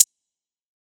Hat  (4).wav